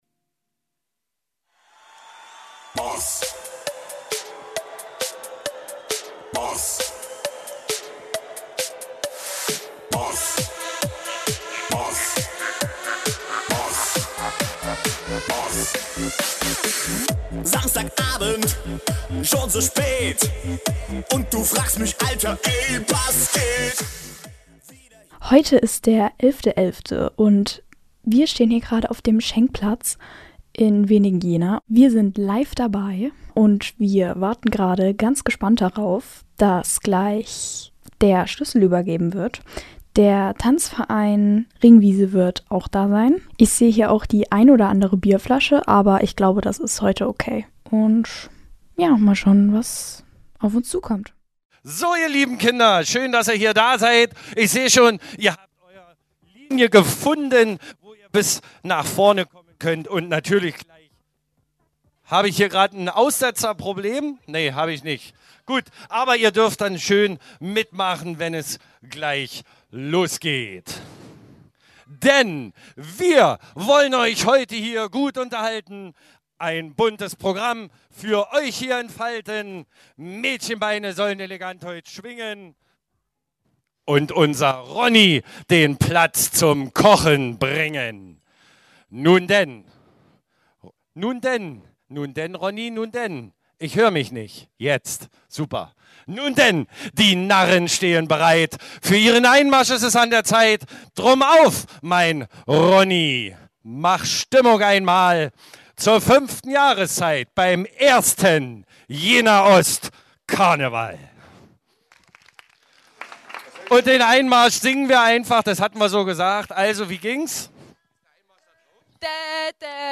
Live vor Ort – Live vor Ort
Um 13.11 Uhr übergab Ortsteilbürgermeister Bastian Stein dem Faschingsverein Ringwiese und den Kindern des Viertels den Schlüssel zum Auftakt der Fünften Jahreszeit auf dem Schenkplatz. Radio OKJ war live dabei.